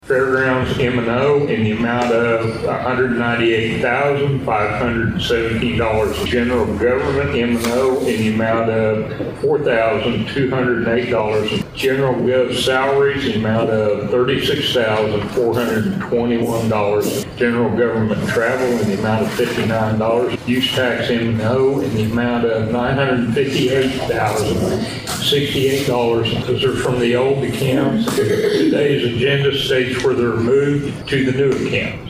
The Board of Osage County Commissioners convened for a regularly scheduled meeting at the fairgrounds on Monday morning.
At that meeting, the board signed a resolution that makes changes so that fairground funds will be in compliance with the chart of accounts. District three commissioner Charlie Cartwright